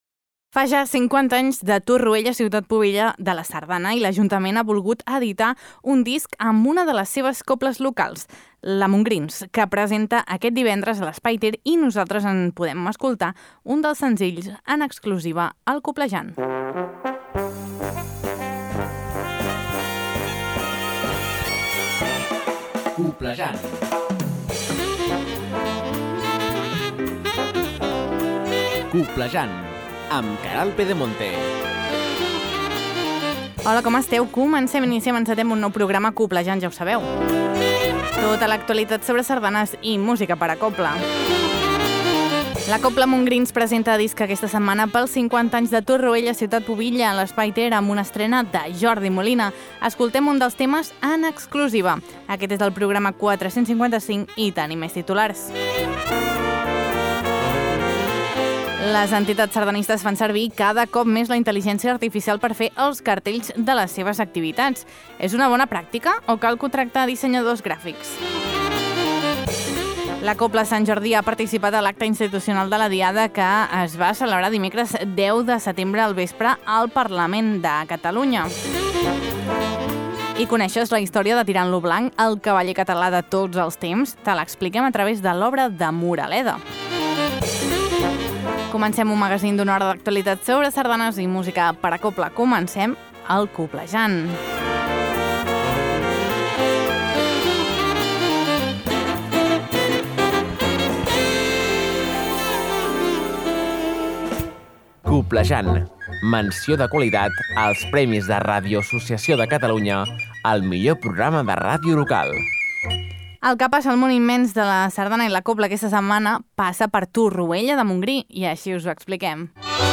Tot això i molt més a Coblejant, un magazín de Ràdio Calella Televisió amb l’Agrupació Sardanista de Calella per a les emissores de ràdio que el vulguin i s’emet arreu dels Països Catalans.